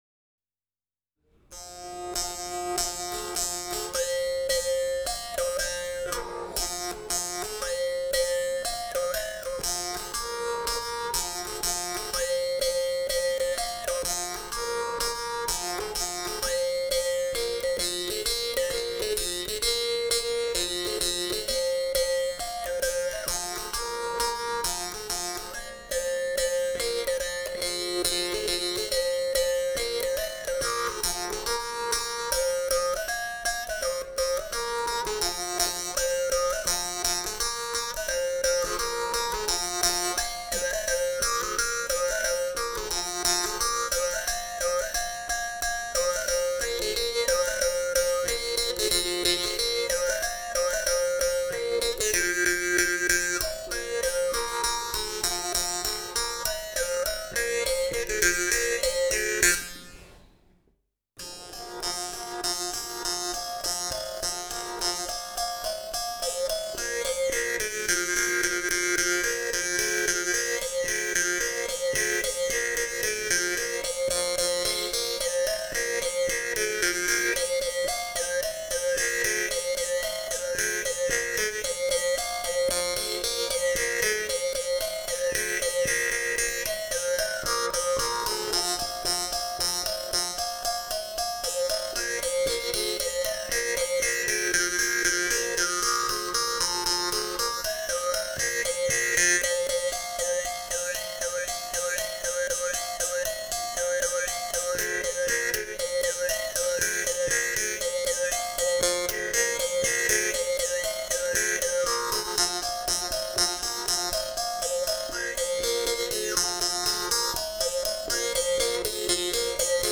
口弦二首